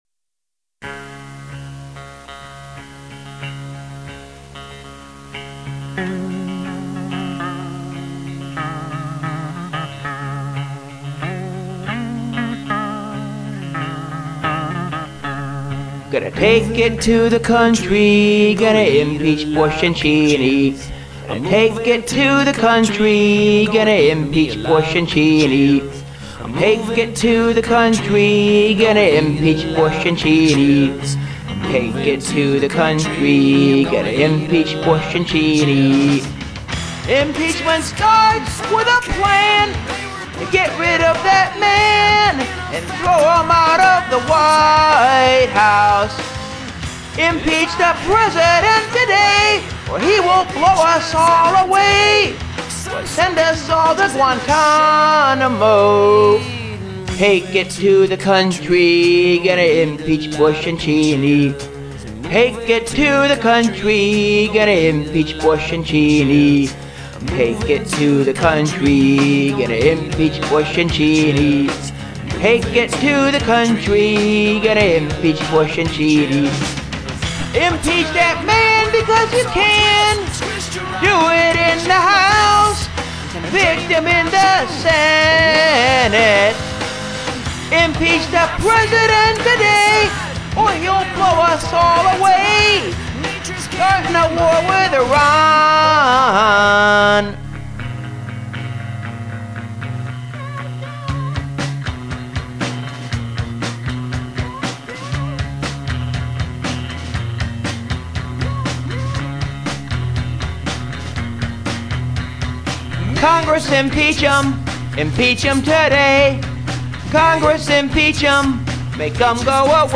Here's a campy little song promoting impeachment